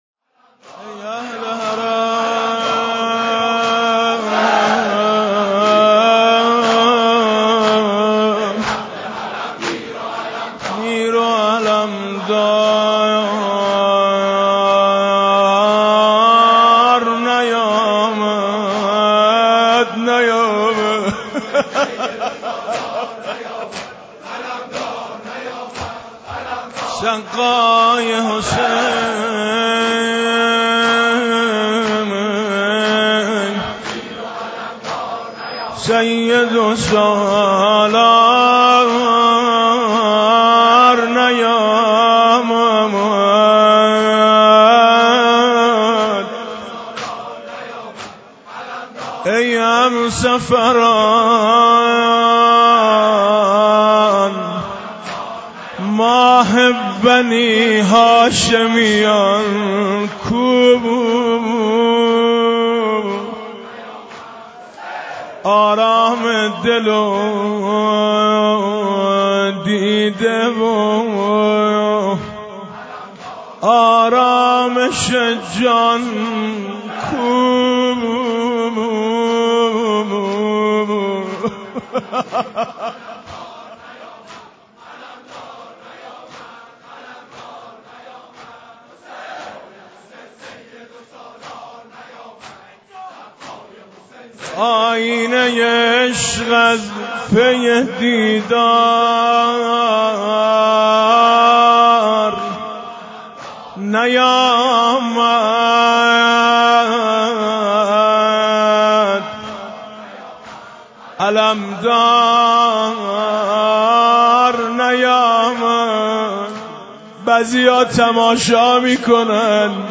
فایل‌های صوتی شب دهم محرم الحرام سال1438 هیئت میثاق با شهدا با نوای حاج میثم مطیعی آماده دریافت است.
دانلود مداحی میثم مطیعی ظهر تاسوعا